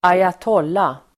Ladda ner uttalet
ayatolla substantiv, ayatollah Uttal: [²ajat'ål:a] Böjningar: ayatollan, ayatollor Definition: hederstitel för framstående islamisk dignitär (a high-ranking Muslim priest in Iran) ayatollah substantiv, ayatolla